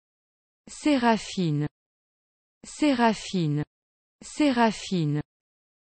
So Séraphine would still be se-ra-FEEN.
Here is how that é sounds in the name Séraphine when pronounced in French: